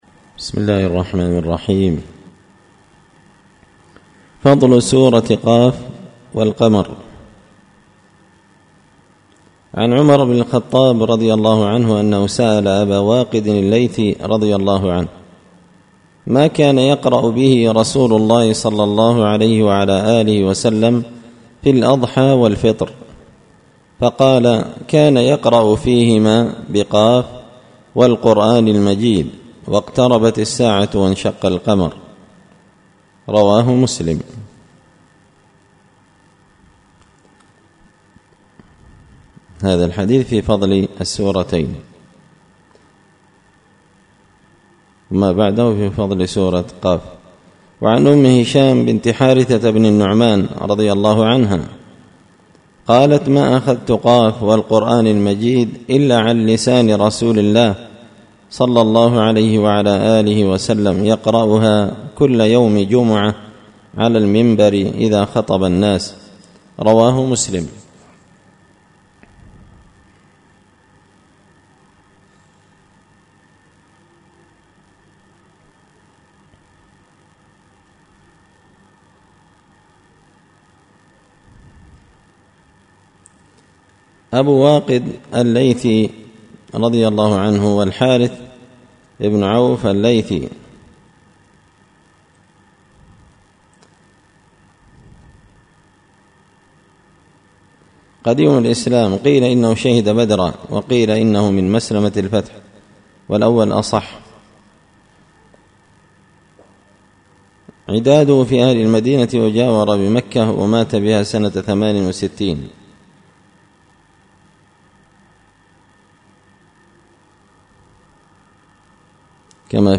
الأحاديث الحسان فيما صح من فضائل سور القرآن ـ الدرس السادس والثلاثون
دار الحديث بمسجد الفرقان ـ قشن ـ المهرة ـ اليمن